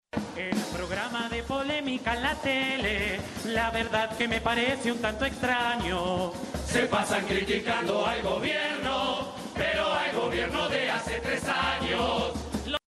Asaltantes con Patente fue la primera murga en presentarse tras las postergaciones de lunes y martes en el Teatro de Verano, por Covid-19 y situación climática respectivamente.